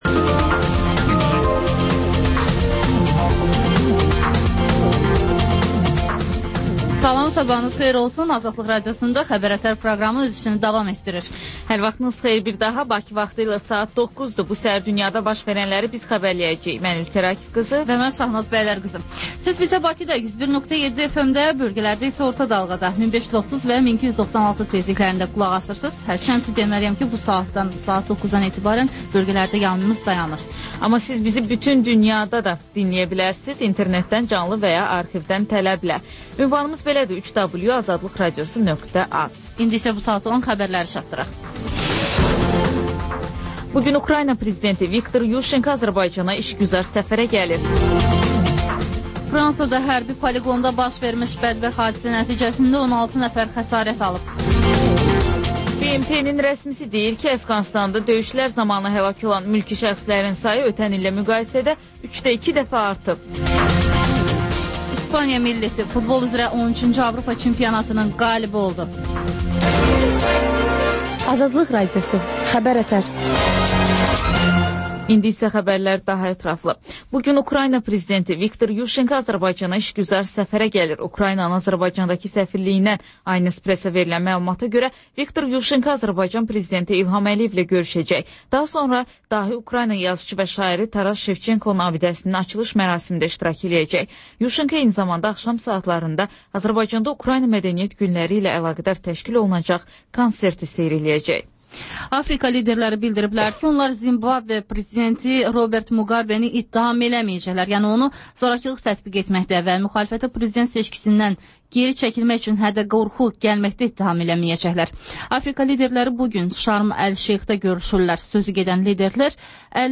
Xəbər-ətər: xəbərlər, müsahibələr, sonra TANINMIŞLAR rubrikası: Ölkənin tanınmış simaları ilə söhbət